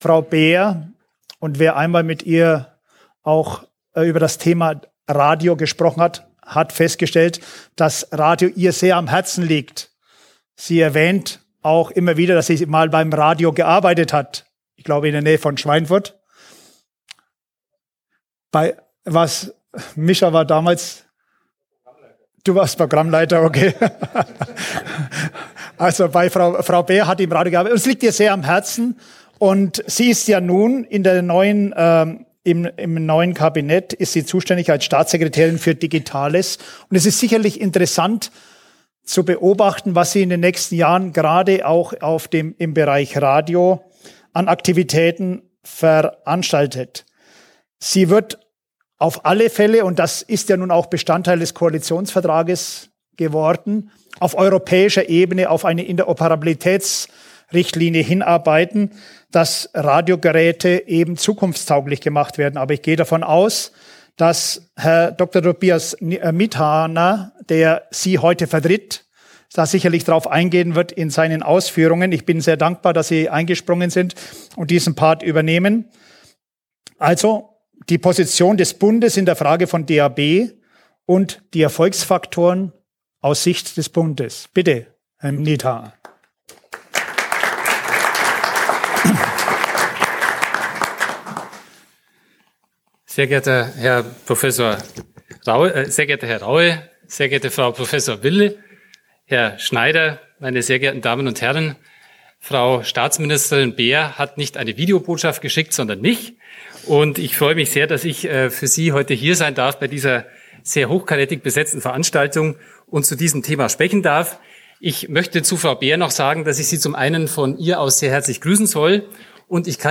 Wer: Heike Raab, Staatssekretärin und Bevollmächtigte beim Bund und in Europa, für Medien und Digitales, Rheinland-Pfalz Was: Videoansprache (Audio) Wo: Landesvertretung Rheinland-Pfalz, Berlin Wann: 14.03.2018, 16:12 Uhr